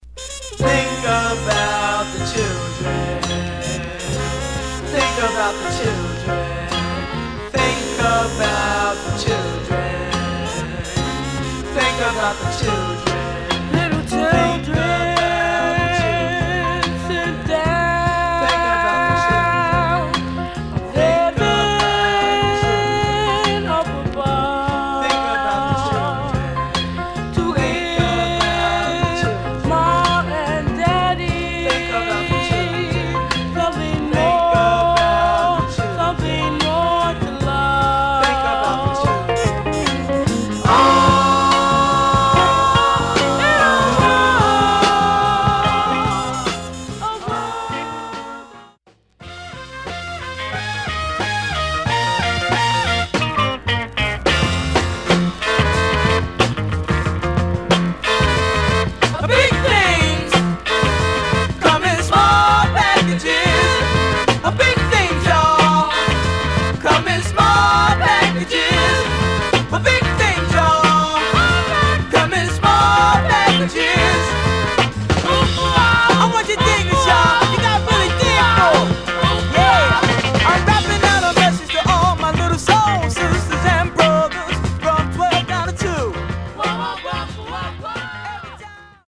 Flip Side Funk Condition: M- DJ
Stereo/mono Mono